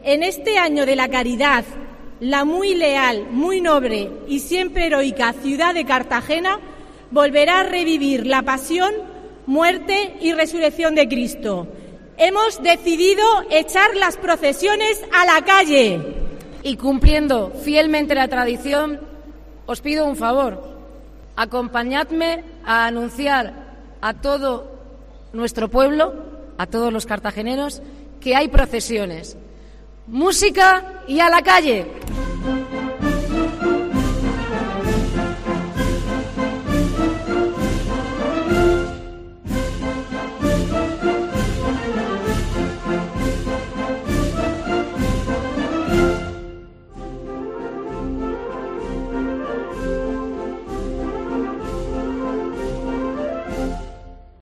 La Llamada de la Semana Santa cartagenera